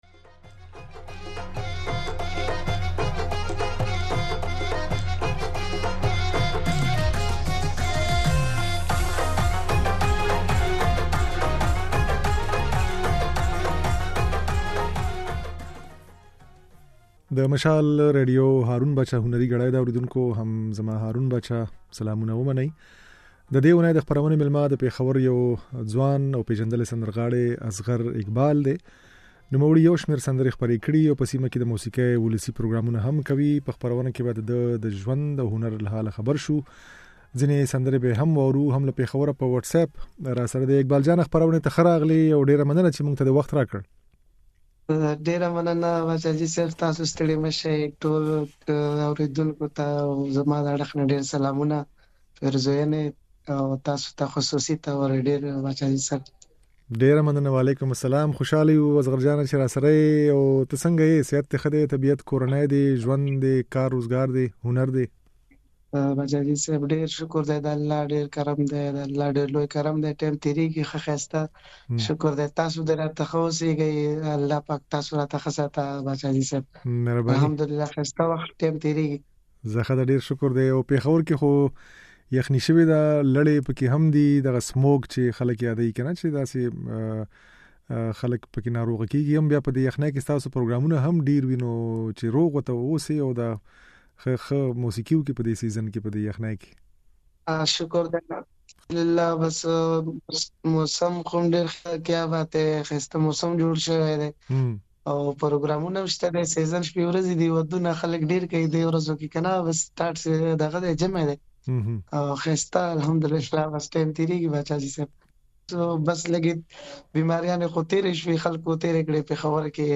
د نوموړي دا خبرې او ځينې سندرې يې په خپرونه کې اورېدای شئ.